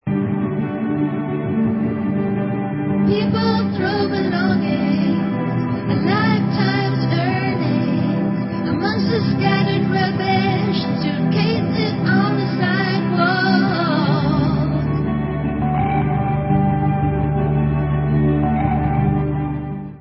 sledovat novinky v oddělení Alternative Rock